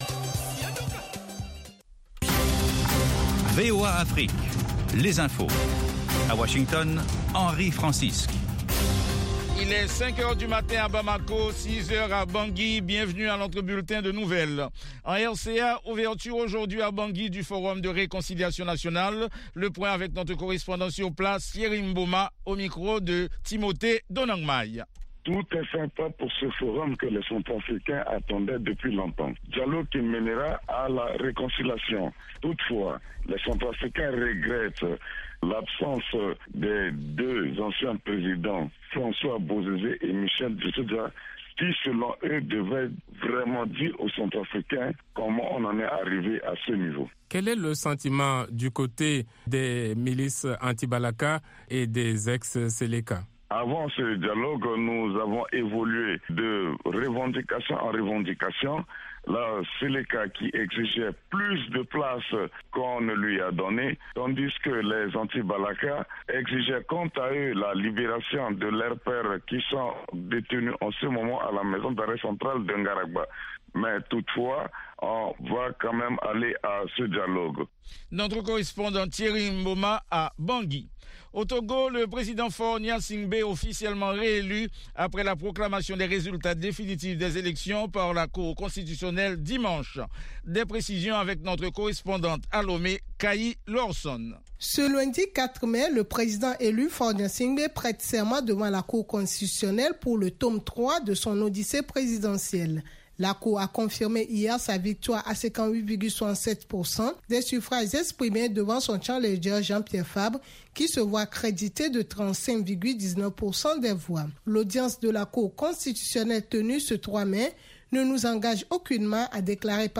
5 min News French